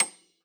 53q-pno30-C7.aif